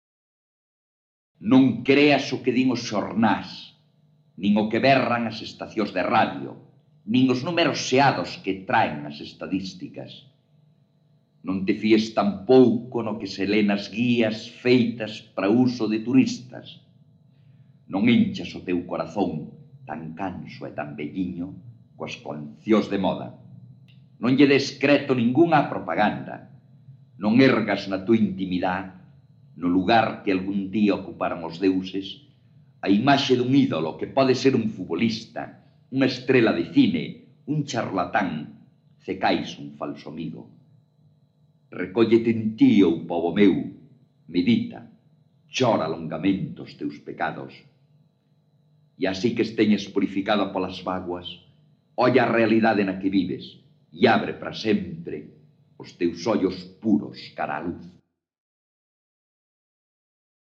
Autoría: Manuel María   Intérprete/s: Manuel María